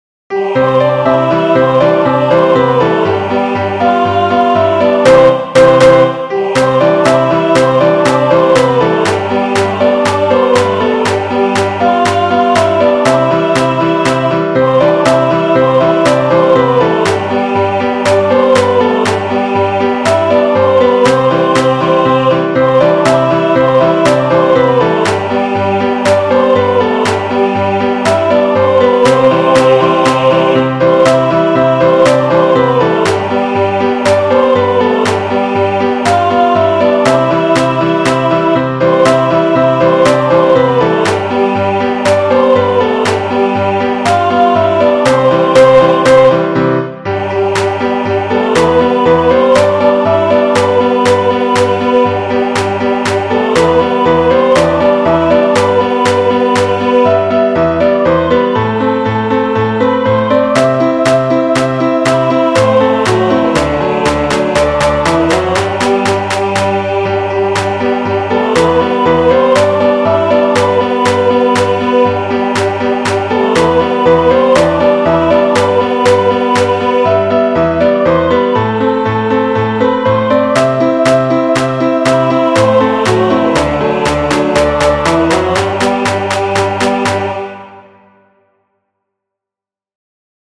3  MARCHAS POPULARES para 2026
Alegre, ritmo  cativante,entra facilmente no ouvido e é convidativa ao acompanhamento.